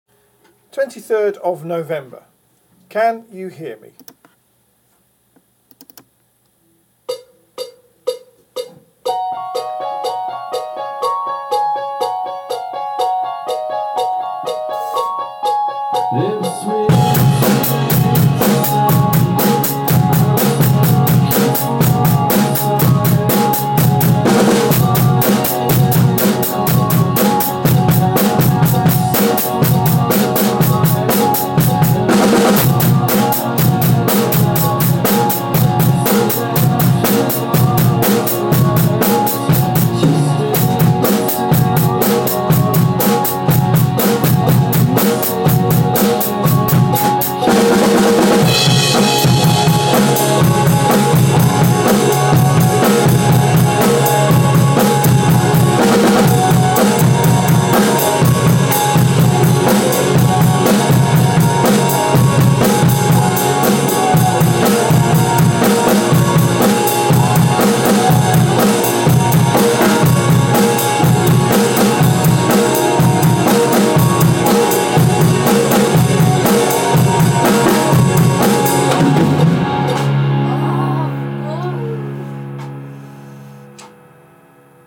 23/11 work in progress .... Just a couple of fills to add...... And the odd groan to remove ...... .?